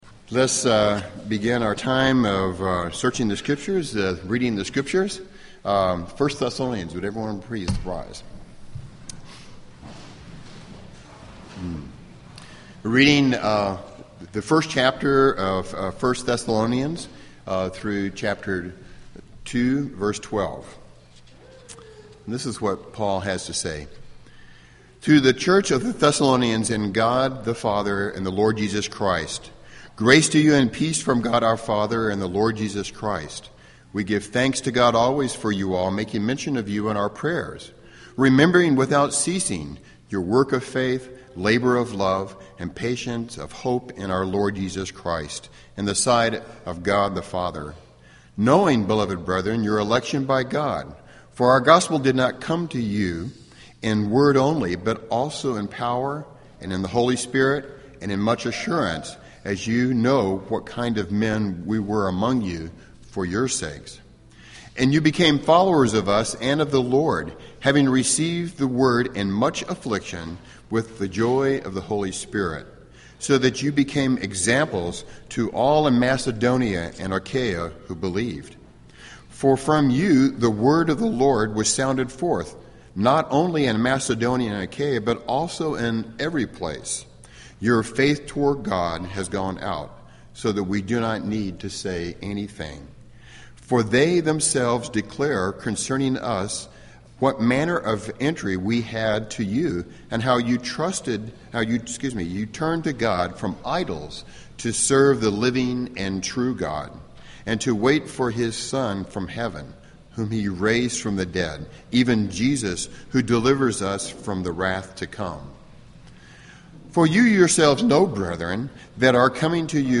preached on Dec. 28, 2014, from 1 Thessalonians 1:1-2:12